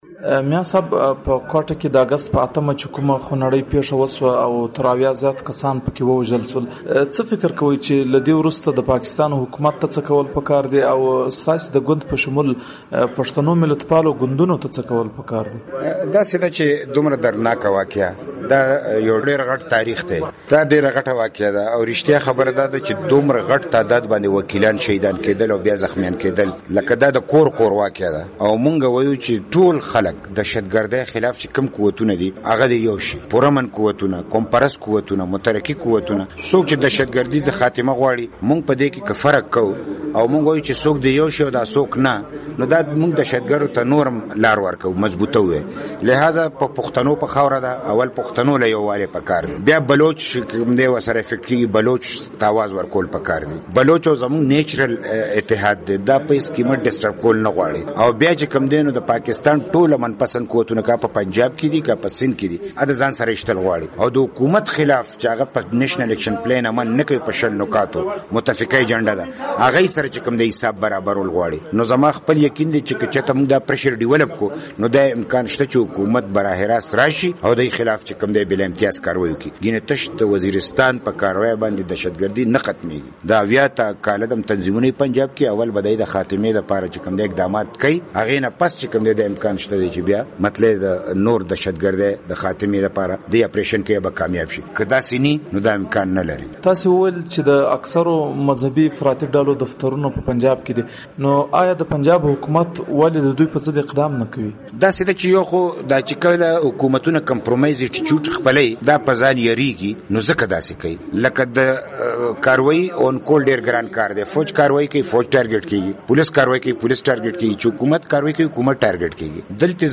مرکه
له میا افتخار حسېن سره مرکه